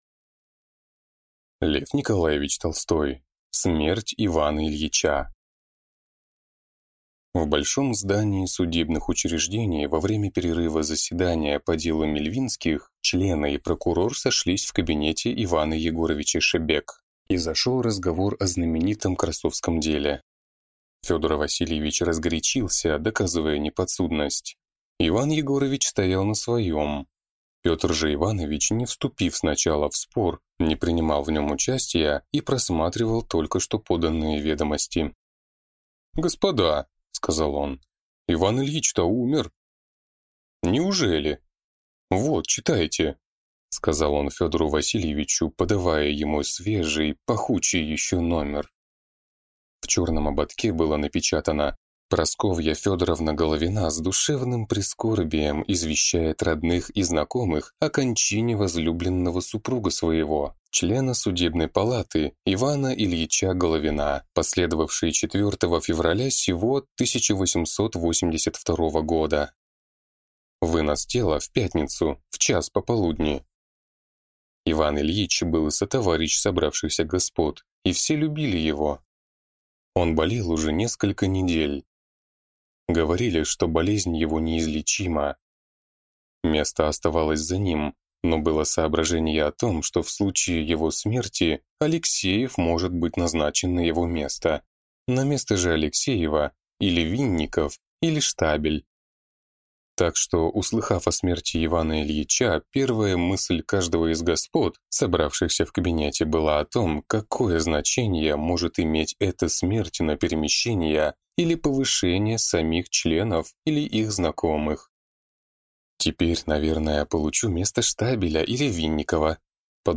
Аудиокнига Смерть Ивана Ильича | Библиотека аудиокниг